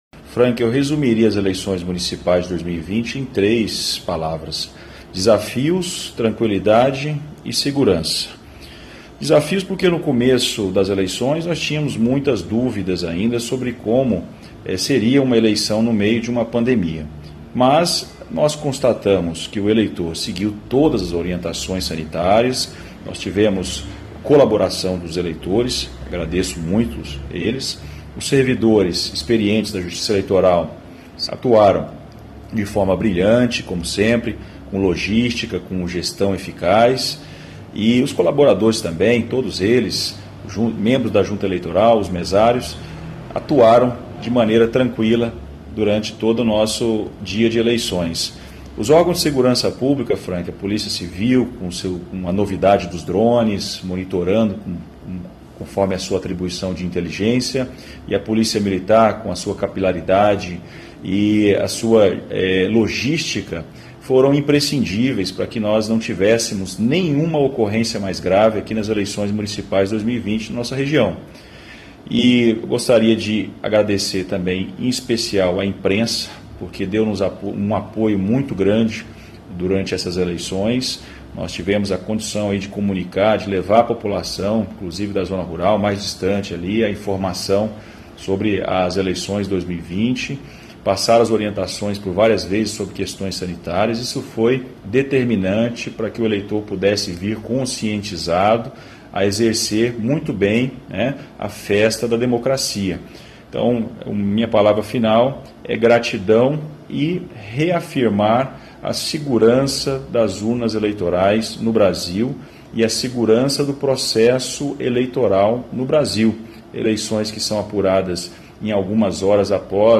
O Juiz Eleitoral, Dr. Rafael Lopes Lorenzoni fez um balanço das eleições a reportagem Veredas.